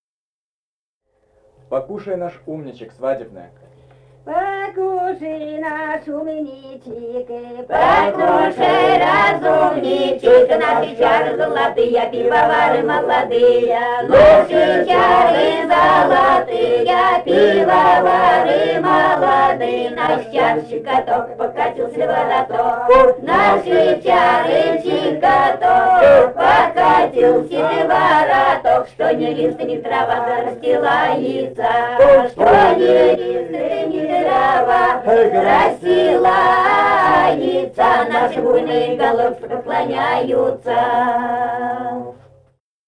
Этномузыкологические исследования и полевые материалы
«Покушай, наш умничек» (свадебная).
Самарская область, с. Кураповка Богатовского района, 1972 г. И1318-18